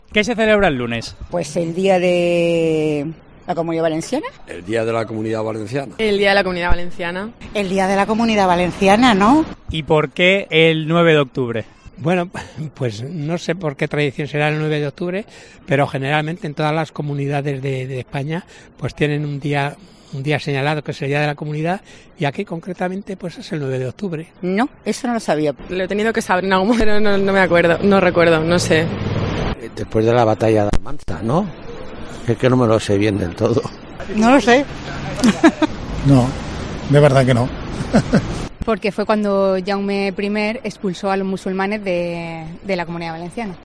Antes de un largo fin de semana con motivo del festivo por el día de la Comunidad Valenciana, COPE ha sacado sus micrófonos a las calles del centro de Alicante para preguntar si los ciudadanos conocen que se conmemora la entrada a la ciudad de Valencia del rey de Aragón Jaime I El Conquistador en 1238.